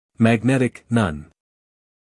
英音/ mæɡˈnetɪk / 美音/ mæɡˈnetɪk /